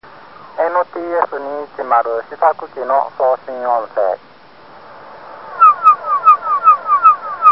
NTS210試作機のSSB変調を144MHz受信機でﾓﾆﾀｰした音声　(n2-210ssb-991109.mp3---37KB)
(マイクからの声と受信スピーカとの間でハウリングさせた音を録音)